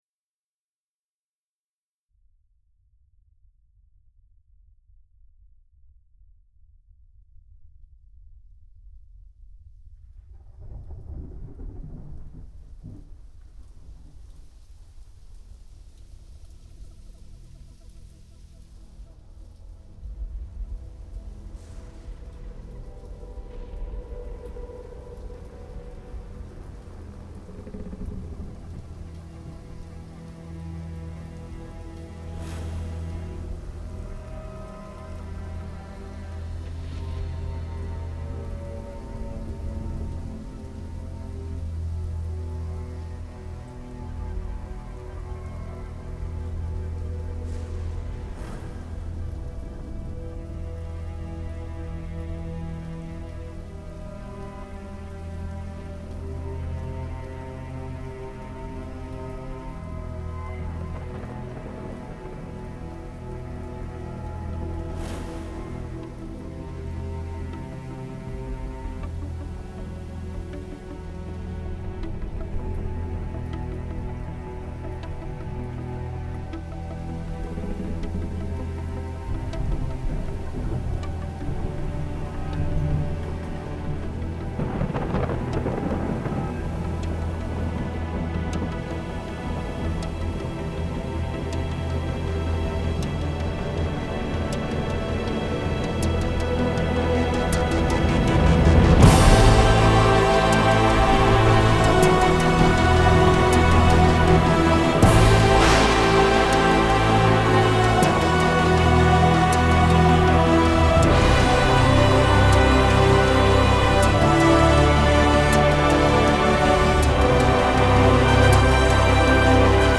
I imagined what it would be like as the storm built up.